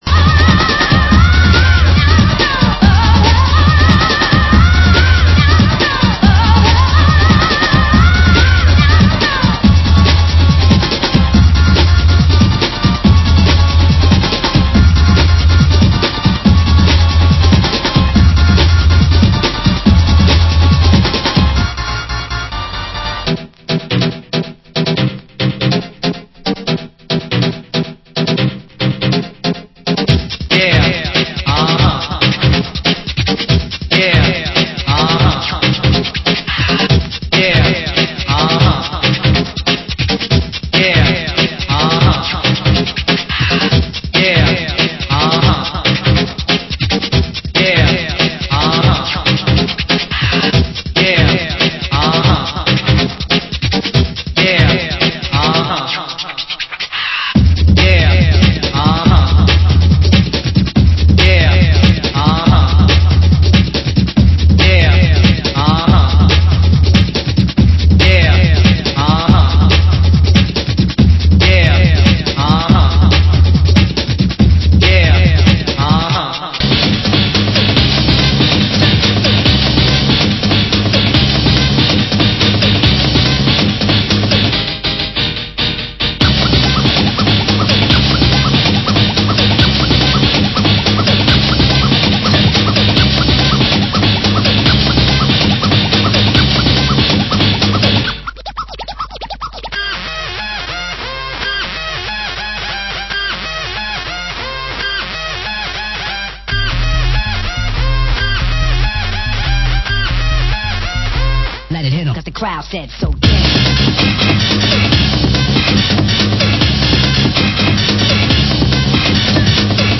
Genre: Hardcore